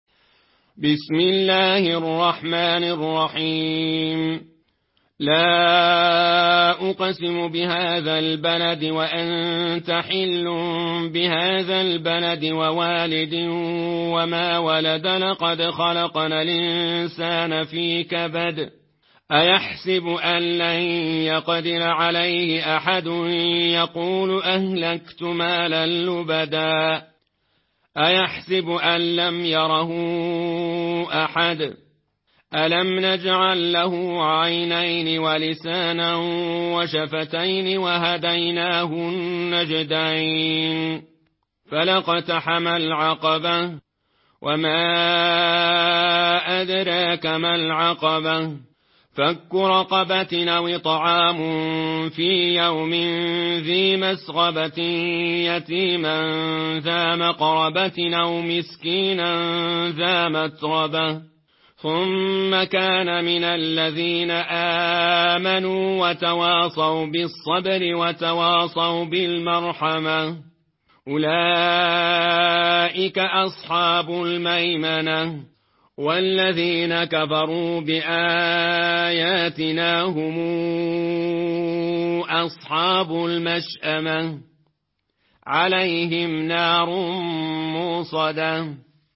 مرتل ورش عن نافع